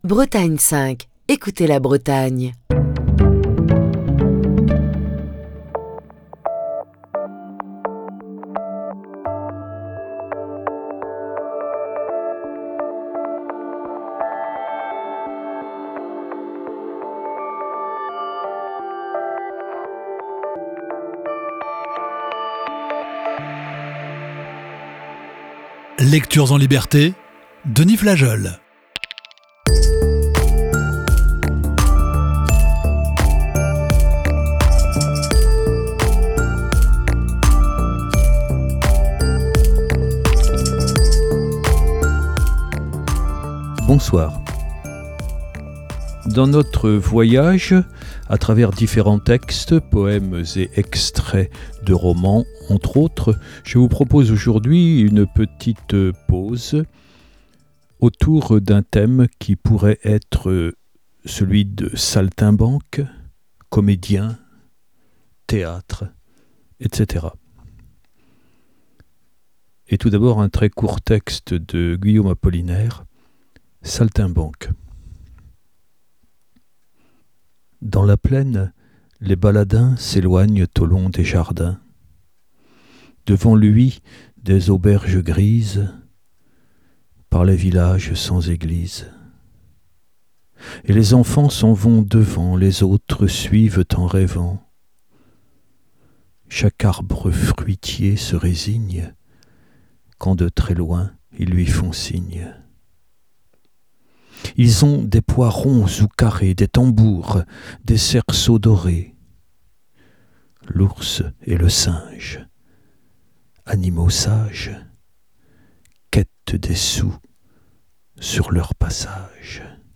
Émission du 25 octobre 2023.